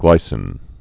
(glīsĭn)